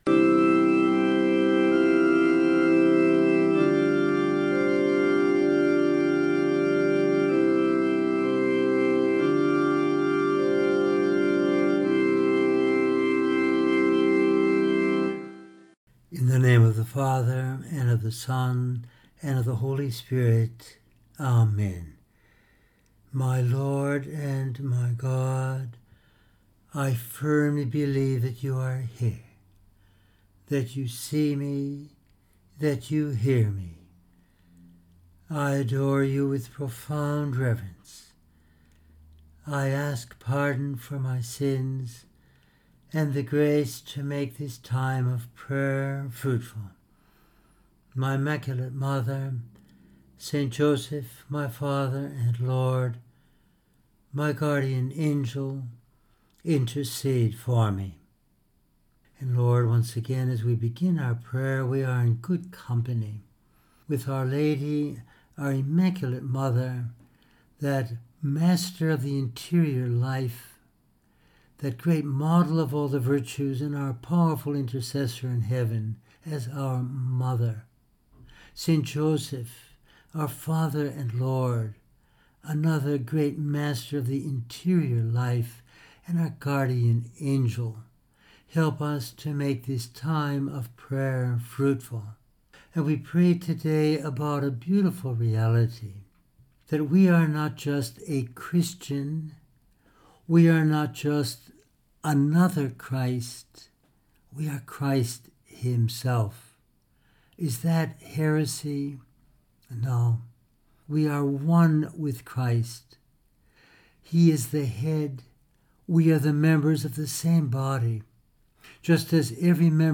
He lives in us and we live in him. In this meditation we use texts of Scripture, St John Eudes, St Josemaria Escriva and St John Paul II to consider how: